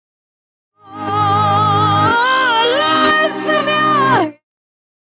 pop rock
Tipo di backmasking Bifronte